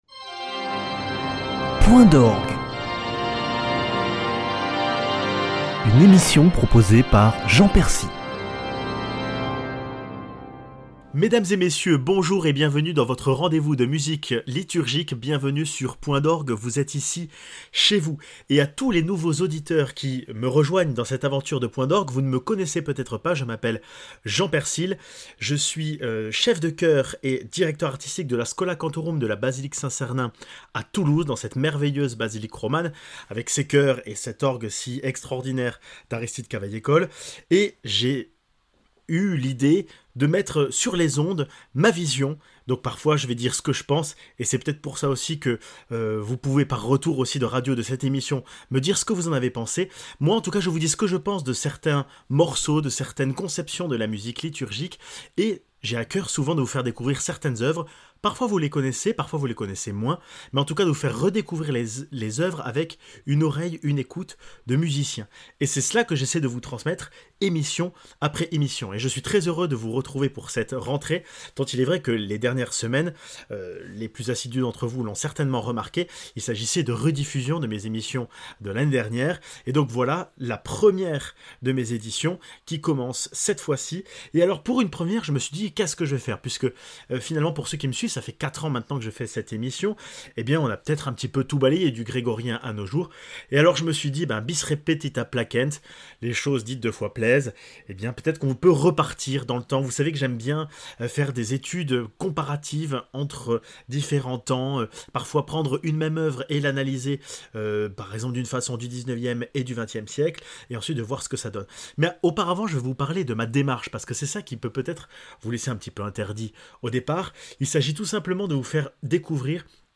Période faste de fête et des danses, la musique sacrée baroque a su, à l’instar de l’opéra, mettre en scène la splendeur de Dieu (Danse des sauvages des Indes Galantes de Rameau et Dixit Dominus de Haendel).